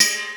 Perc_05.wav